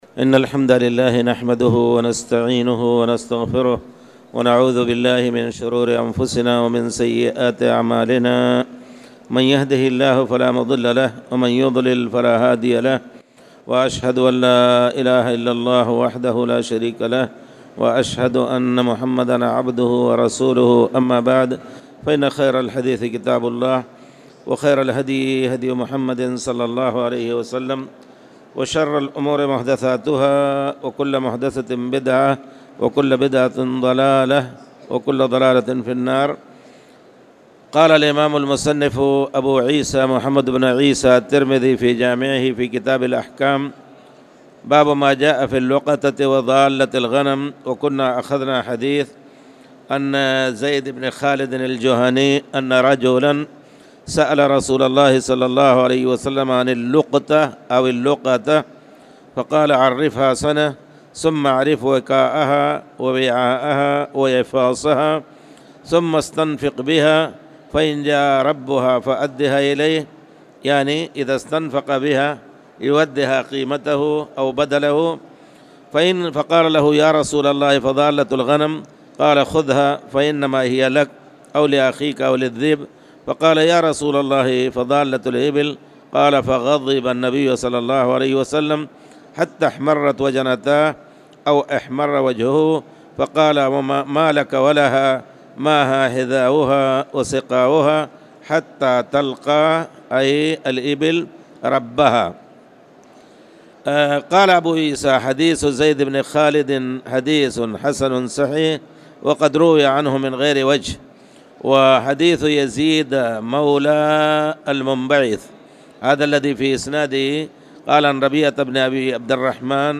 تاريخ النشر ٢٦ جمادى الأولى ١٤٣٨ هـ المكان: المسجد الحرام الشيخ